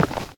step_stone.3.ogg